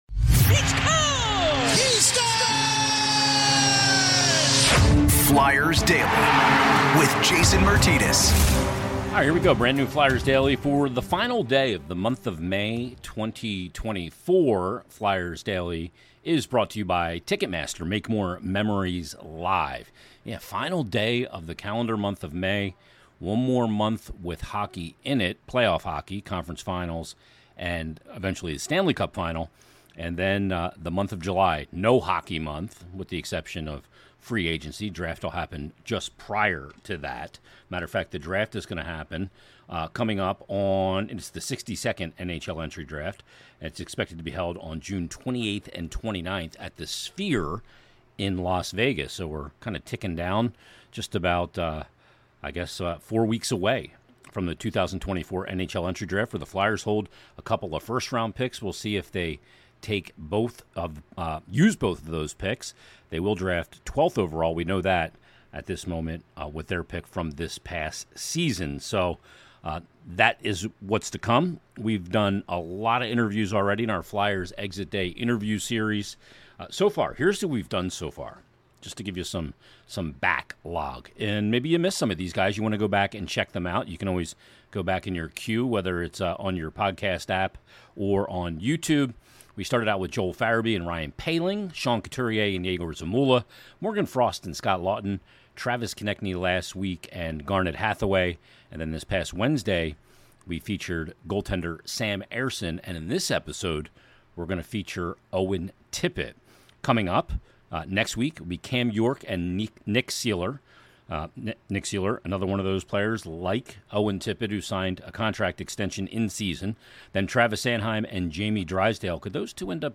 Our Flyers Exit Day Interview Series continues with Forward Owen Tippett. Owen discusses everything from just missing out on 30 goals for the season, adding more weapons to his offensive arsenal, committing to Philadelphia long term and much more.